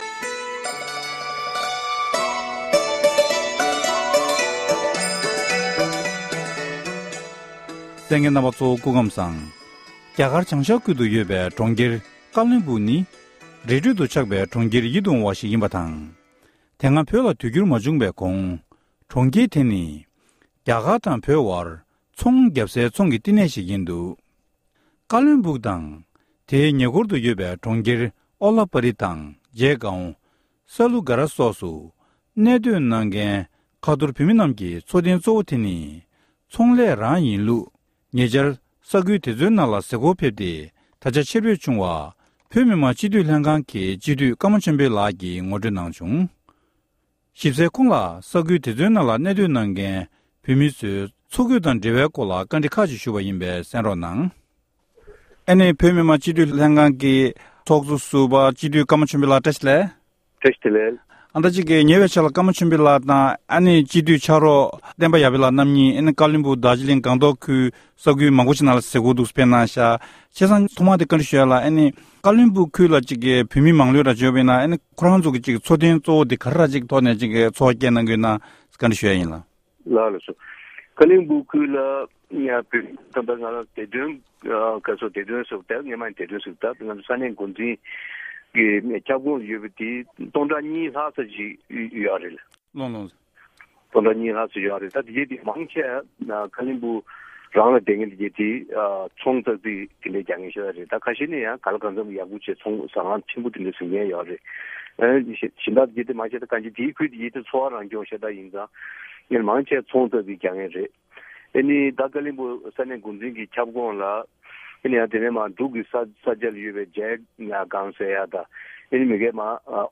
བཀའ་འདྲི་ཞུས་པ་ཞིག་གསན་རོགས༎